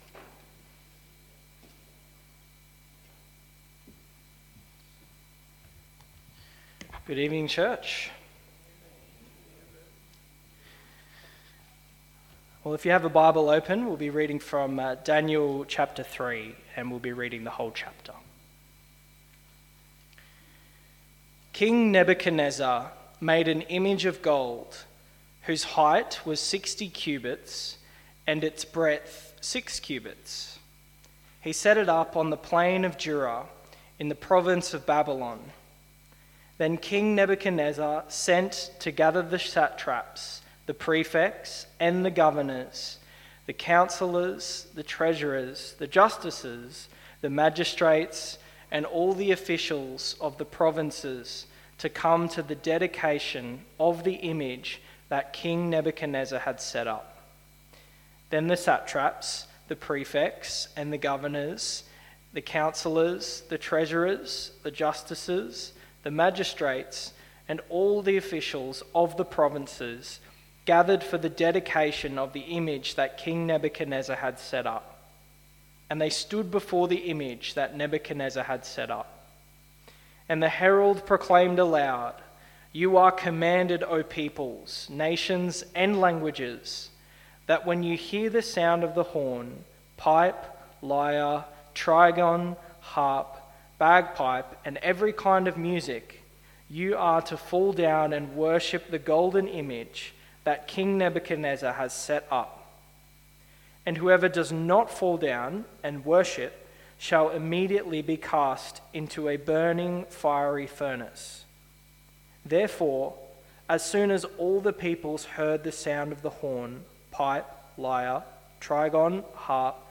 How To Stand Firm In The Face Of Fiery Trials PM Service